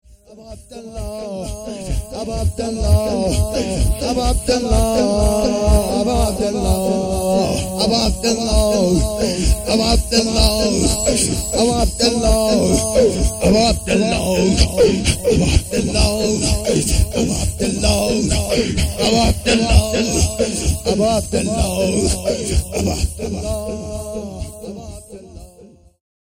نوا
محرم الحرام ۱۴۴۳